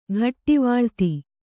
♪ ghattivāḷti